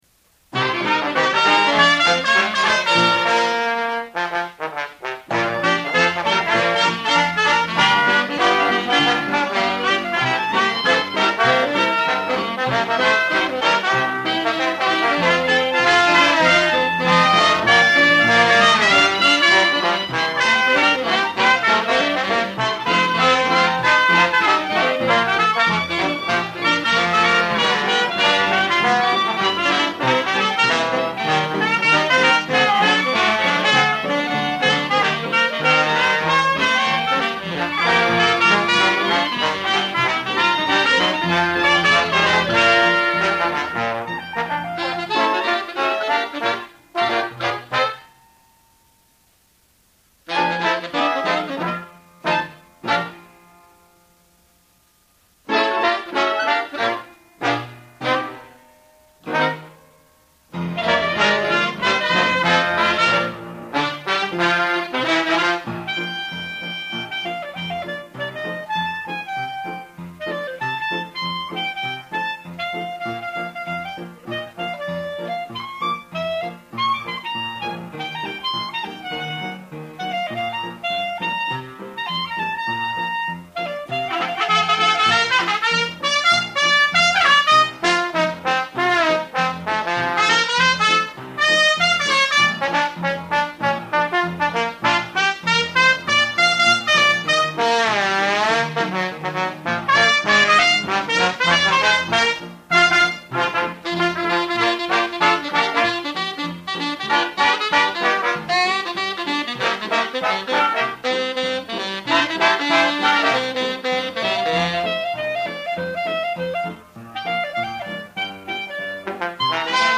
High School Dixieland Band at White Sulphur Springs, Montana
Piano
Trumpet
Clarinet
Sax
Trombone
Drums
Audio is compromised by acoustic echo and record scratches